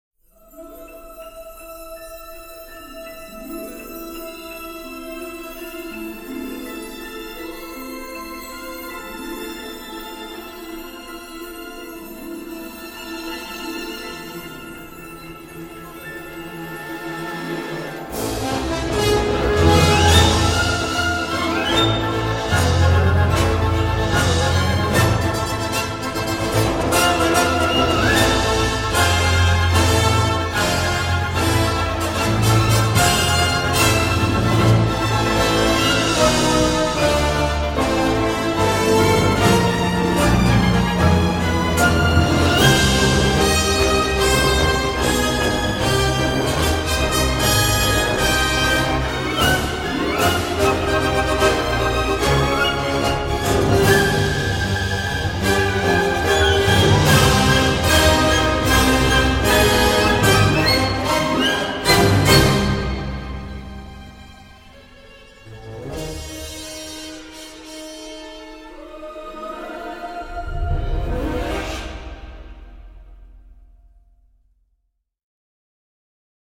C’est carré, symphonique et surtout bien agréable à écouter.